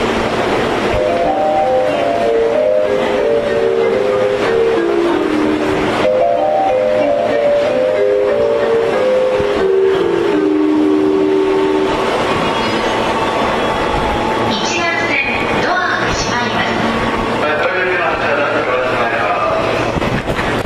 発車メロディが更新されました。
曲名不明 東海道線新橋駅のメロディと同じです。